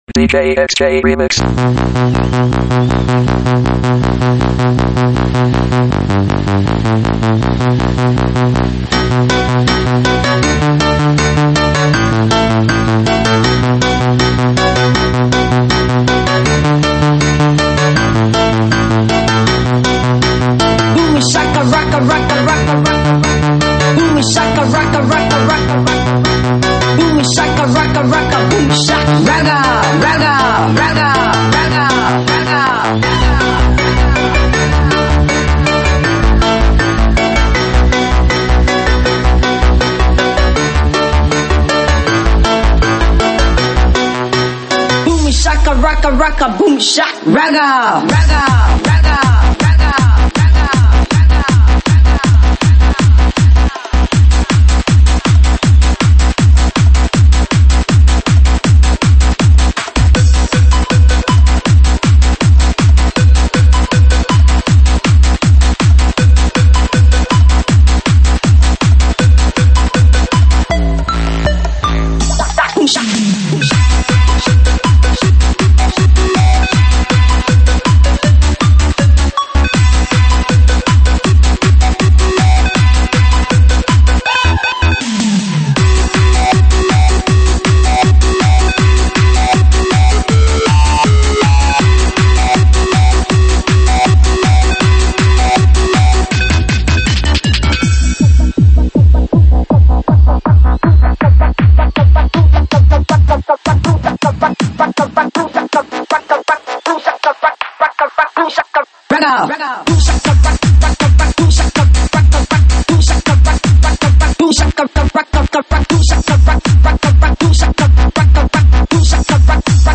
收录于(现场串烧)
舞曲类别：现场串烧